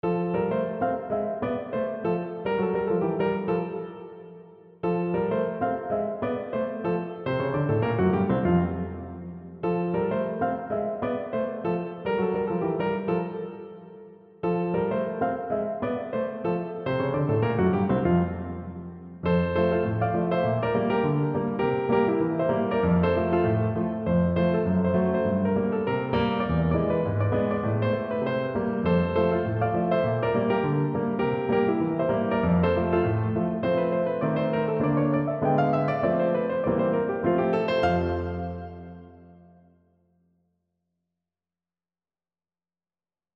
pre klavír